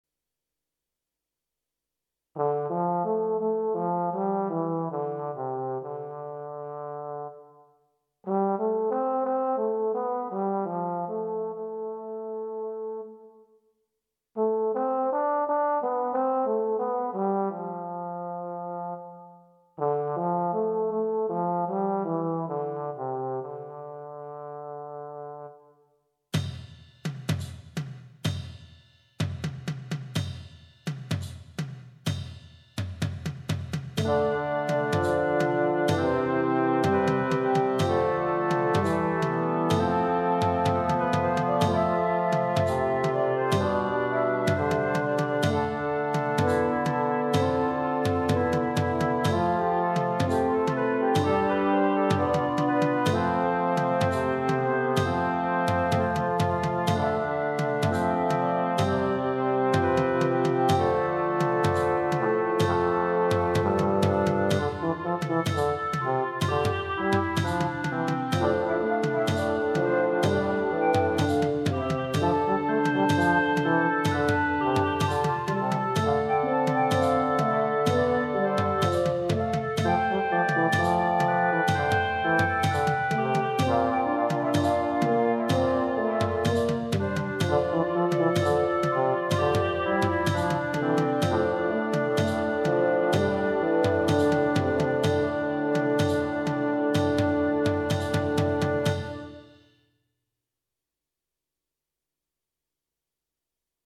minus Instrument 2